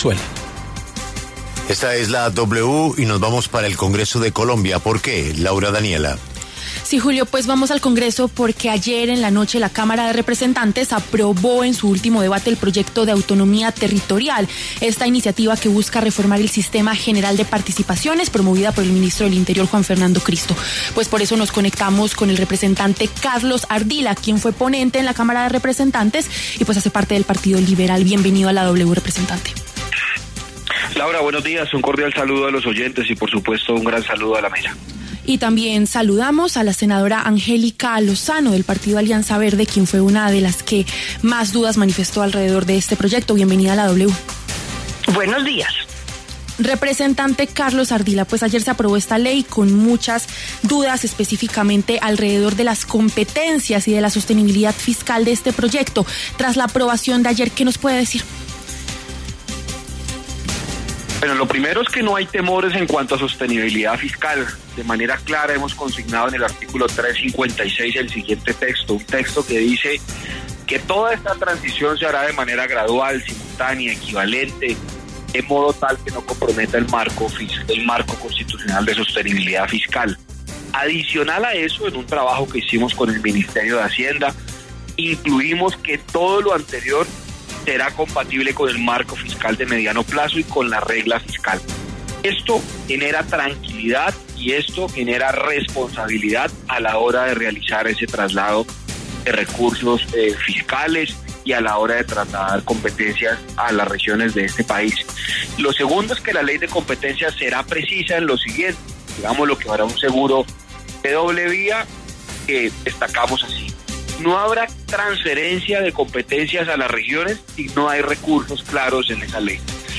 Debate congresistas: ¿Estado podría entrar en quiebra tras aprobación de reforma al SGP?
El representante liberal Carlos Ardila, ponente del proyecto, y la senadora Angélica Lozano, de la Alianza Verde, pasaron por los micrófonos de La W.